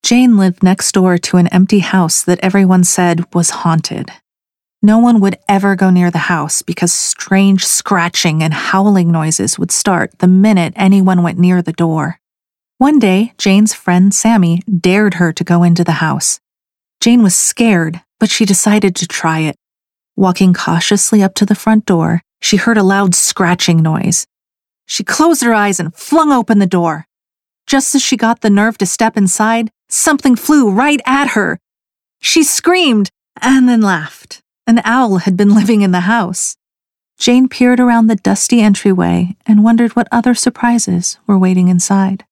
Childerns narrative demo
Young Adult
Middle Aged